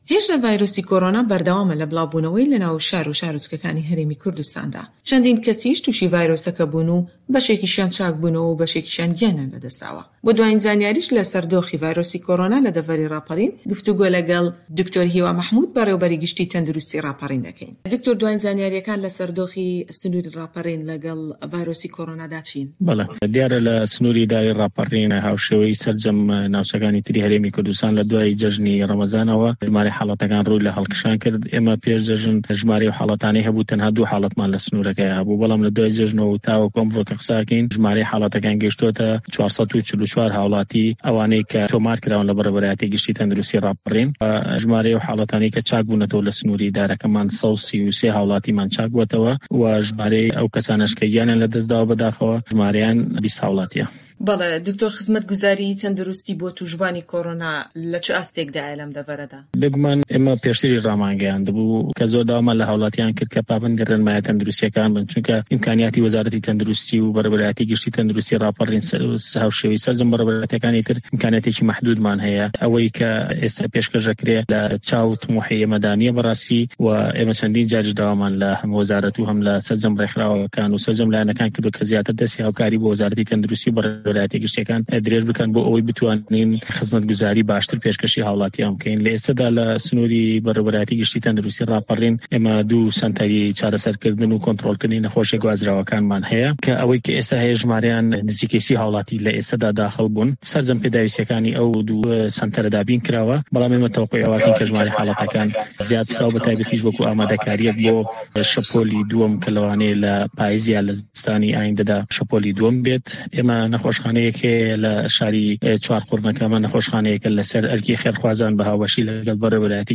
درێژەی ووتوێژەکە لەم فایلە دەنگیەی خوارەوەدایە.